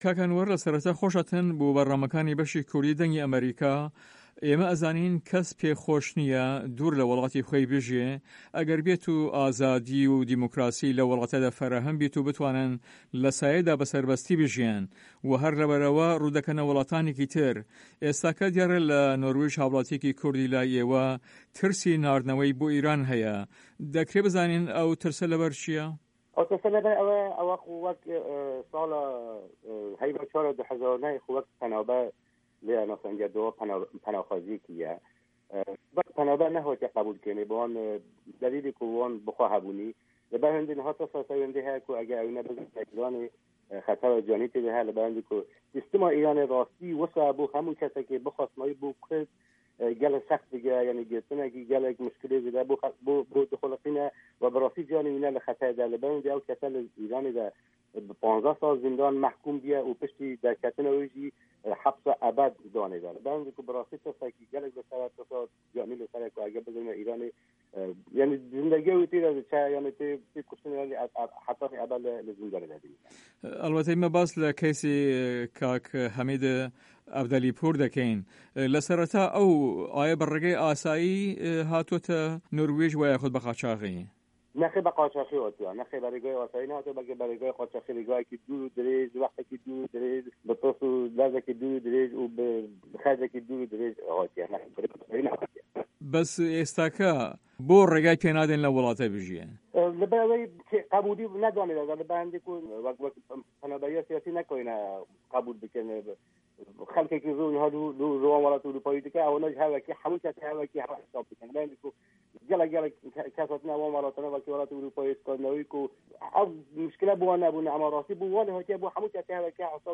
هه‌ڤپه‌یڤینێکدا له‌گه‌ڵ به‌شی کوردی ده‌نگی ئه‌مه‌ریکا